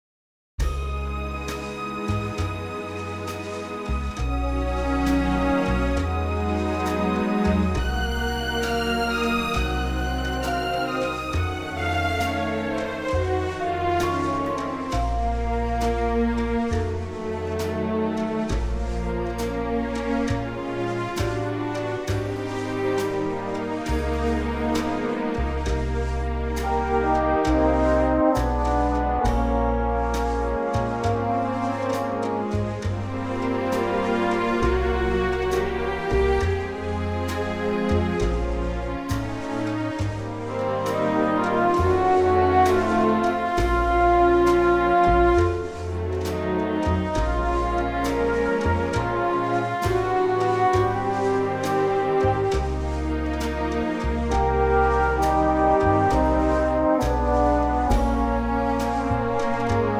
key - Ab - vocal range - E to Bb